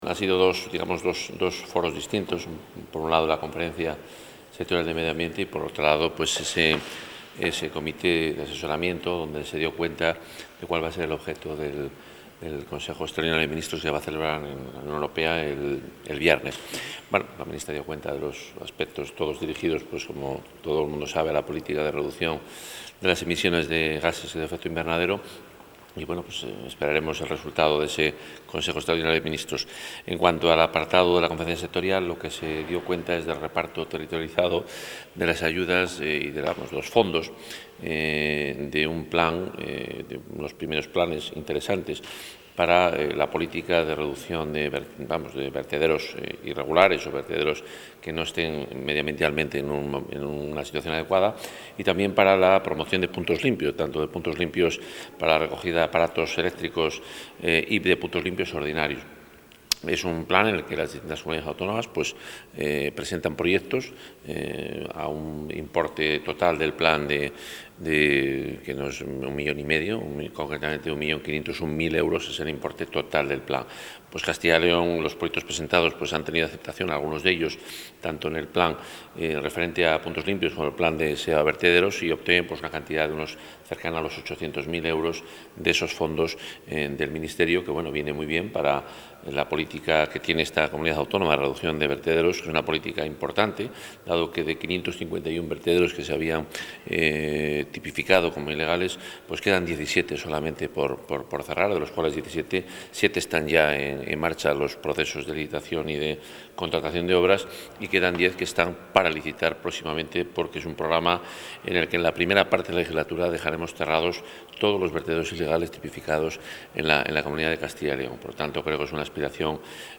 Valoración del consejero de Fomento y Medio Ambiente tras las reuniones sectoriales mantenidas hoy en Madrid